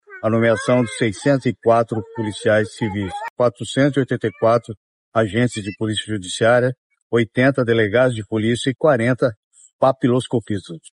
O secretário da Segurança Pública, Hudson Leôncio Teixeira, fala sobre as funções que esses novos policiais irão exercer.